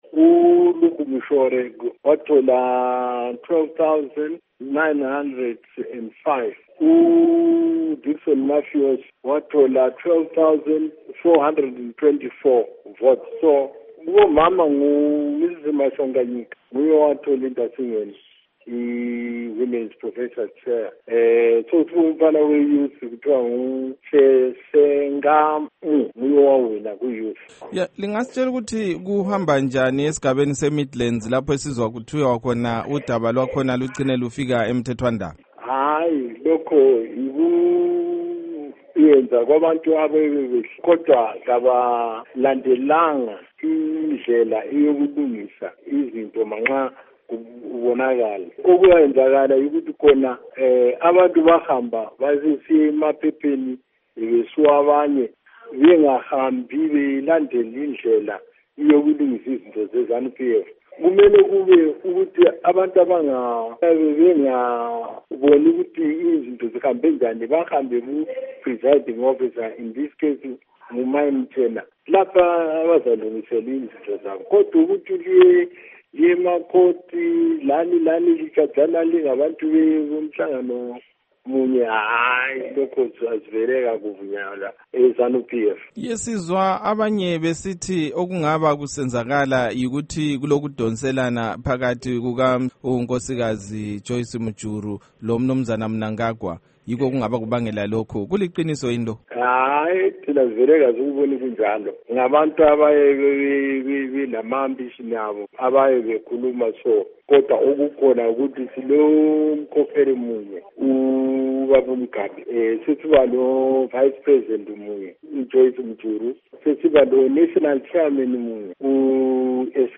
Ingxoxo LoMnu. Rugare Gumbo